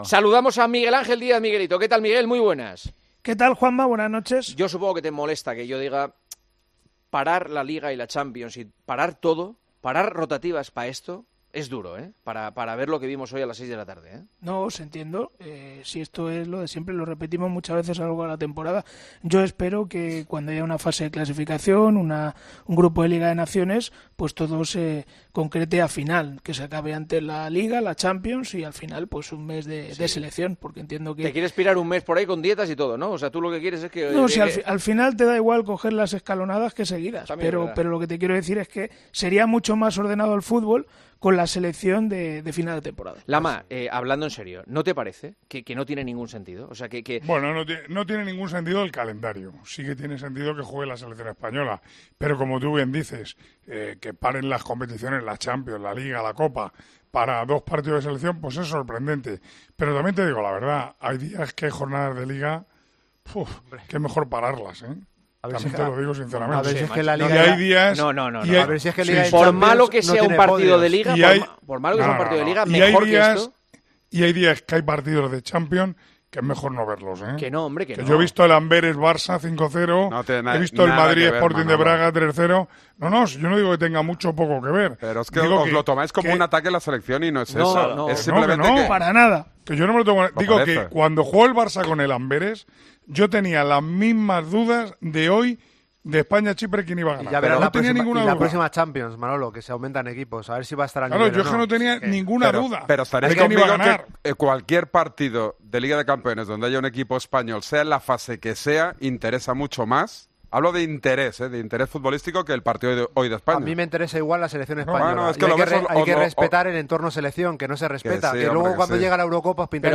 AUDIO: El equipo de comentaristas de El Partidazo de COPE valoran el encuentro del combinado de Luis de la Fuente ante Chipre.